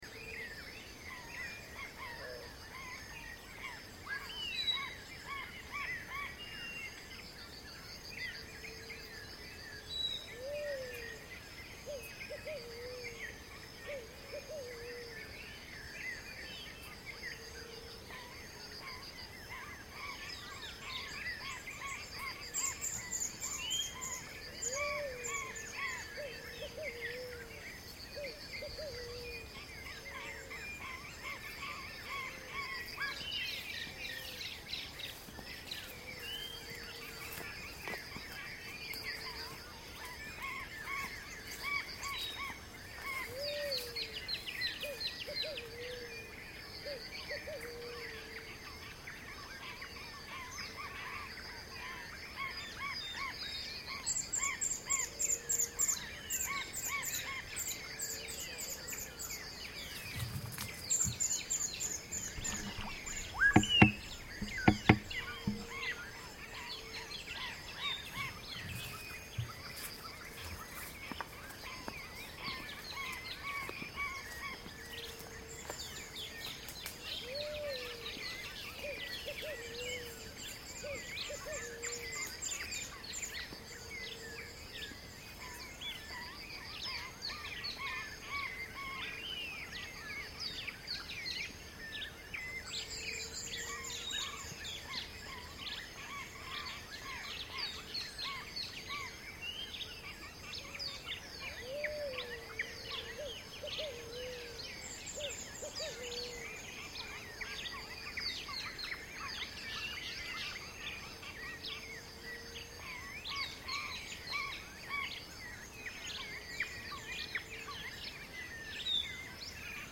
Xocotitla Bird Sound
We can hear the song and calls of different species of birds at sunrise in the Xocotitla natural reserve, in the municipality of Paso de Ovejas, Veracruz, Mexico. This is a voluntary conservation reserve characterized by the presence of low deciduous forest and pastures. You can also hear frogs, insects and other anthropogenic sounds.